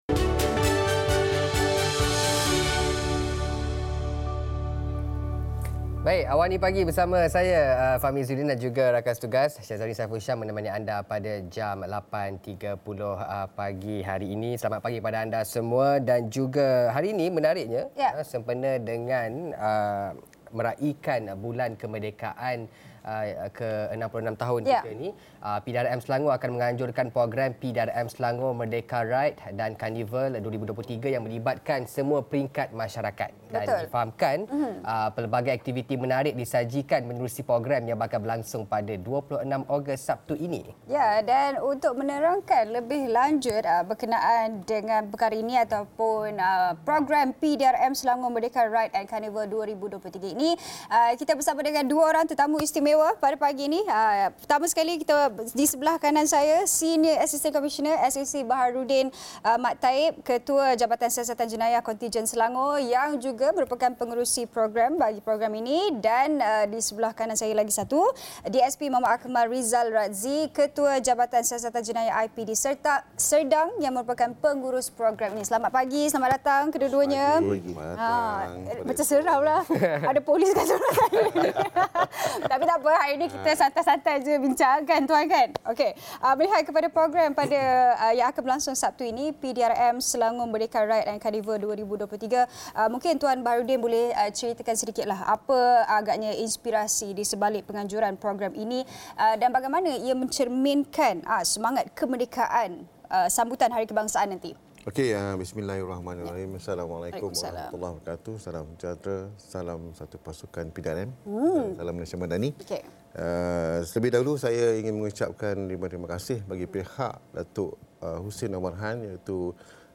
Ketua Jabatan Siasatan Jenayah Selangor, SAC Baharudin Mat Taib, akan berkongsi perincian program 'PDRM Selangor Merdeka Ride & Karnival 2023', yang akan berlangsung Sabtu ini sempena meraikan Bulan Kemerdekaan Malaysia yang ke-66. Ikuti diskusi 8.30 pagi nanti.